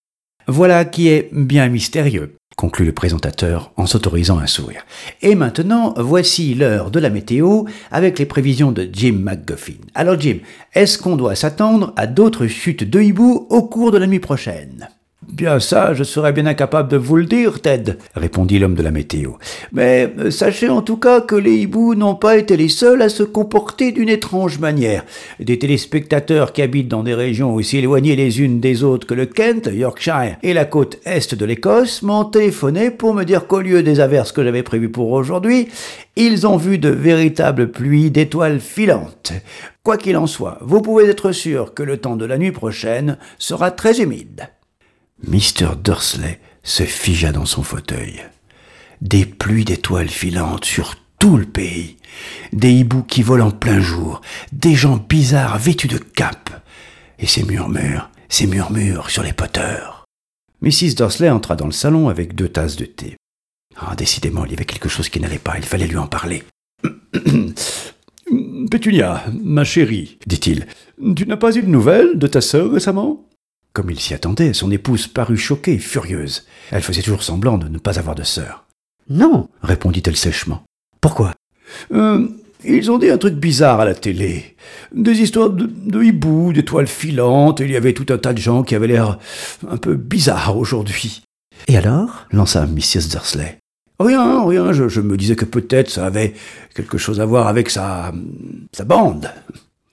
Genre : Pub.